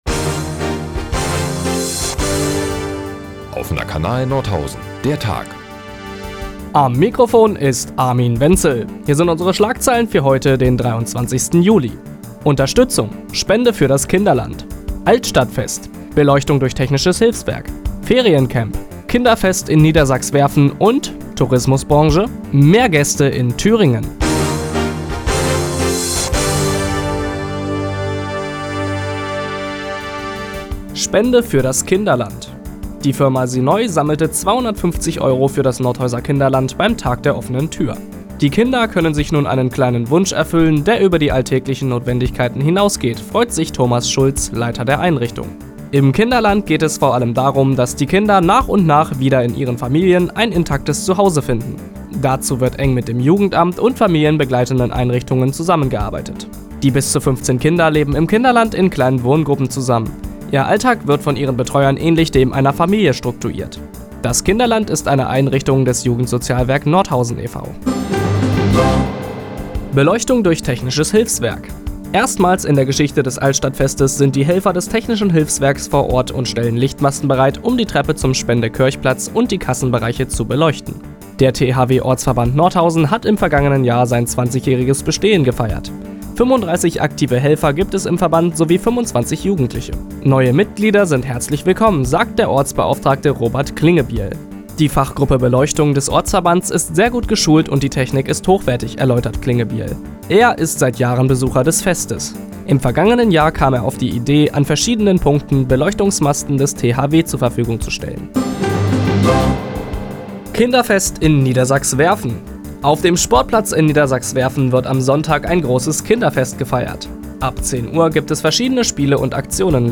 Die tägliche Nachrichtensendung des OKN ist jetzt hier zu hören.